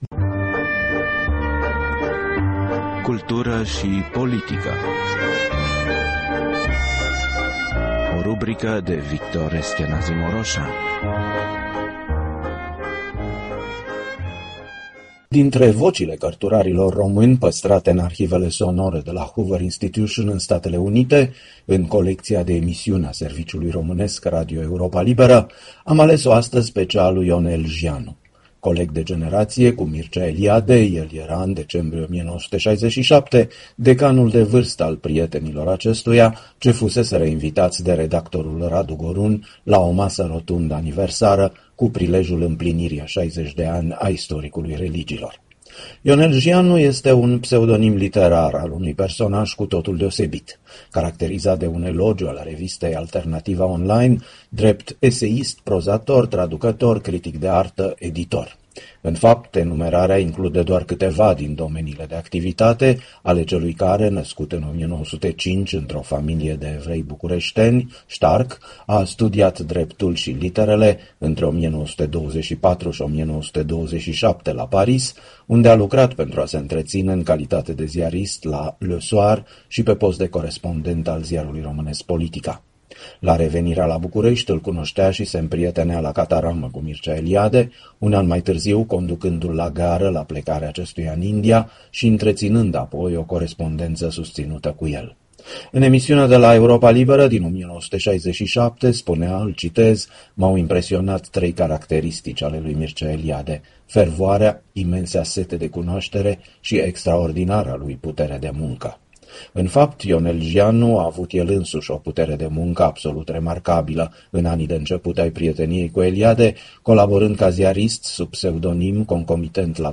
Drintre vocile cărturarilor români păstrate în arhivele sonore de la Hoover Institution, în Statele Unite, în colecția de emisiuni a Serviciului românesc Radio Europa Liberă, am ales-o astăzi pe cea a lui Ionel Jianu (1905-1993).
L-ați ascultat pe cărturarul și istoricul de artă Ionel Jianu vorbind la microfonul Europei Libere în decembrie 1967.